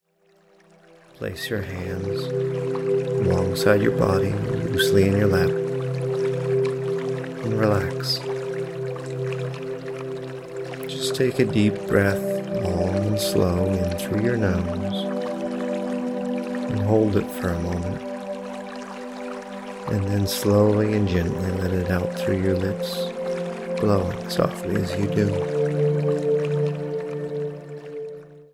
Set to the sounds of a gentle, babbling brook, along with gentle calming music and narrated hypnotic suggestion, it’s the perfect escape from life’s stress.
Peaceful_Hypnotic_Relaxatoin_Stream_Sample.mp3